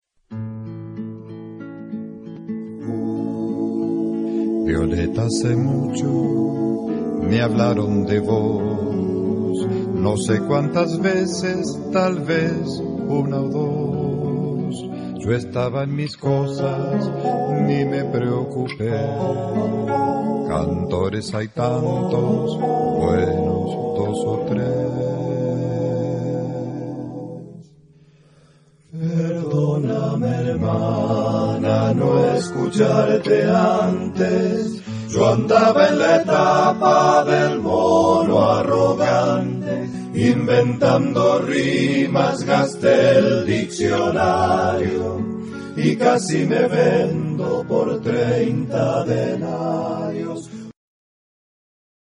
Genre-Style-Forme : Profane ; Chanson
Caractère de la pièce : affectueux
Type de choeur : SSAATTBB  (5 voix mixtes )
Tonalité : la majeur